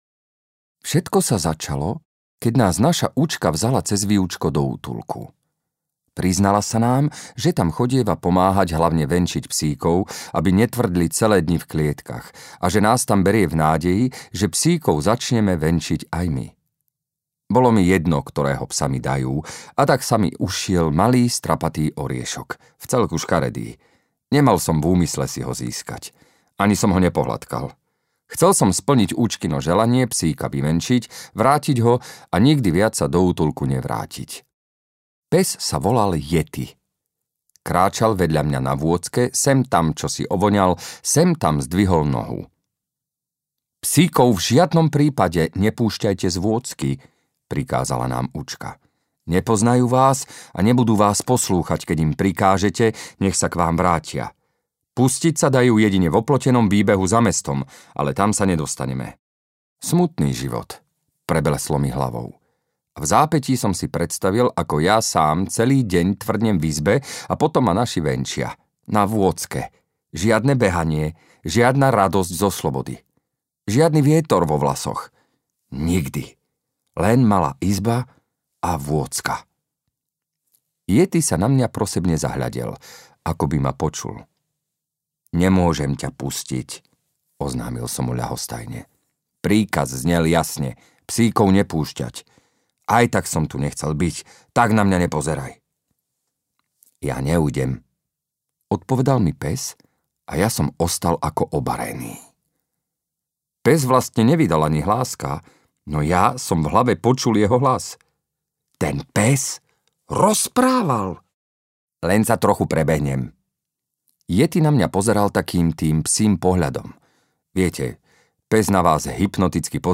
O Chlapcovi, ktorý rozumel psom audiokniha
Ukázka z knihy
• InterpretĽuboš Kostelný